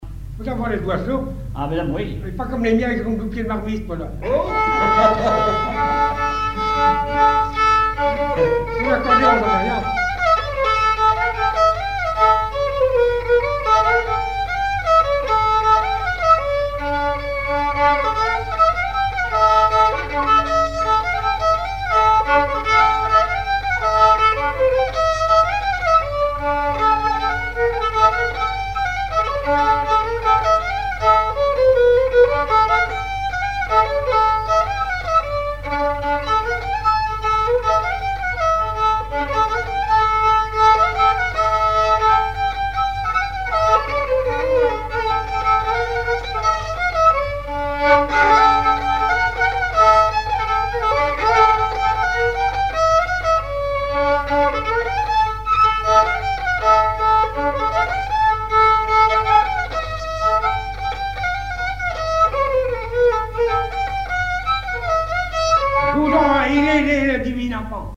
Chants brefs - A danser
danse : sicilienne
chansons populaires et instrumentaux
Pièce musicale inédite